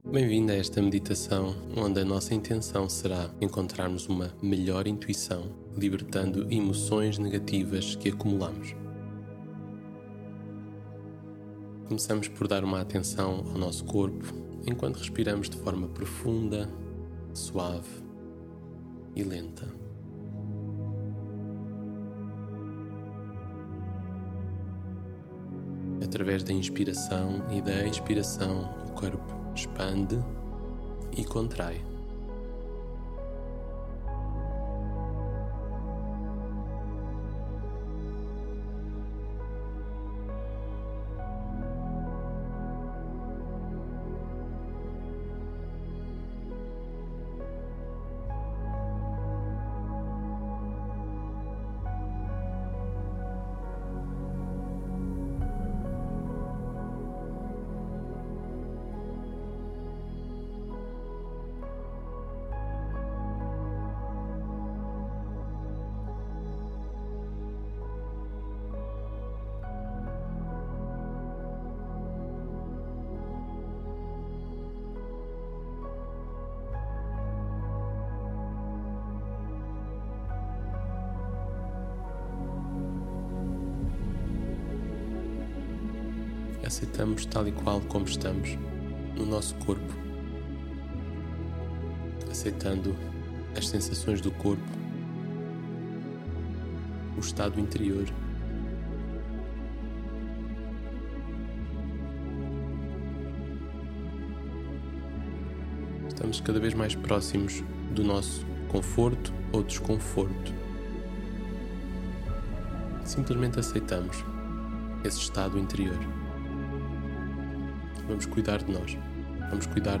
Introdução Realizar Meditação (30m) Recursos Extra: Artigo com os ensinamentos Meditação Breve: Med. Intuição e Confiança (16 m) Downloads / Descarregar: Med.